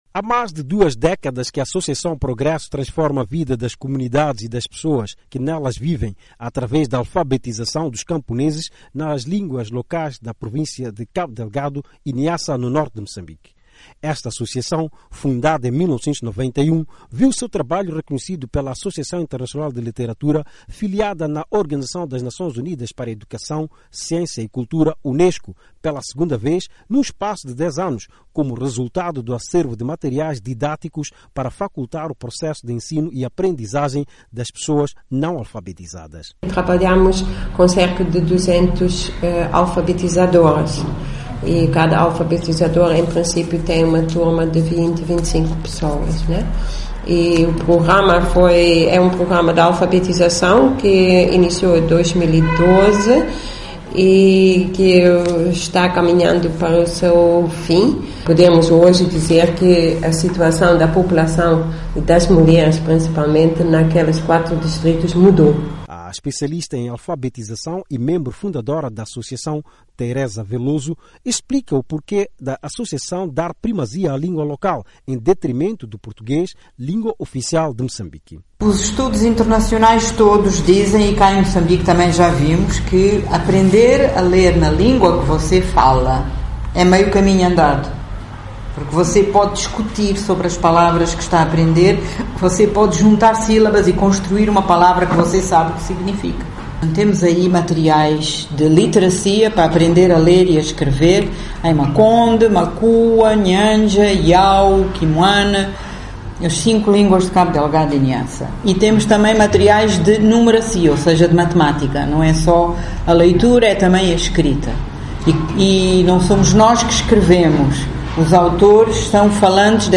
O Ministro da Educação diz que este prémio irá contribuir para incentivar os programas que visam reduzir o analfabetismo em Moçambique.
Para o Governo, na voz do ministro da Educação e Desenvolvimento Humano, Jorge Ferrão, este prémio irá contribuir para incentivar os programas que visam reduzir o analfabetismo em Moçambique.